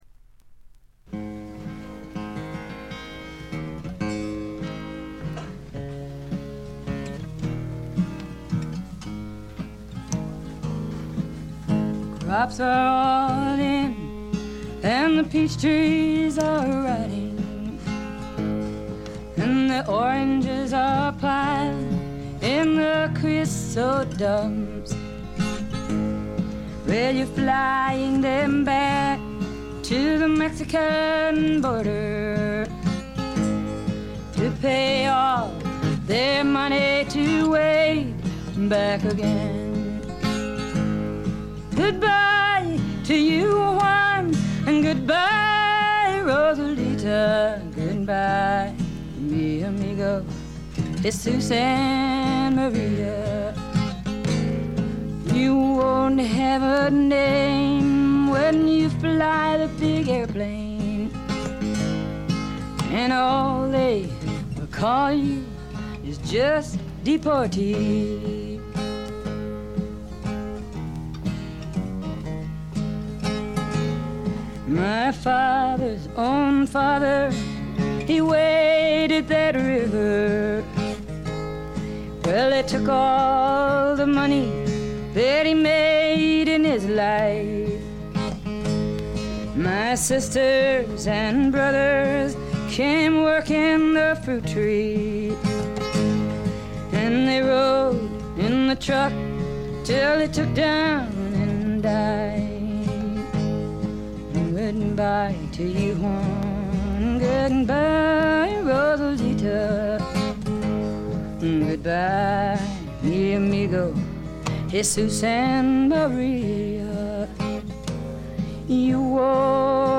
存在感抜群の強靭なアルト・ヴォイスが彼女の最大の武器でしょう。
試聴曲は現品からの取り込み音源です。
Vocals, Guitar